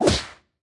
Media:Greg_base_atk_1.wav 攻击音效 atk 初级和经典及以上形态攻击音效
Greg_base_atk_1.wav